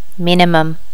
Additional sounds, some clean up but still need to do click removal on the majority.
minimum.wav